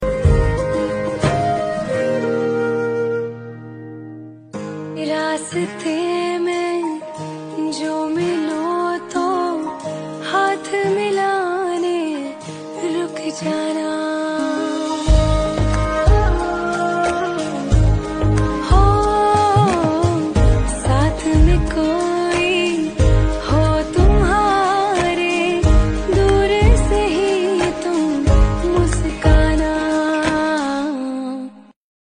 romantic vibes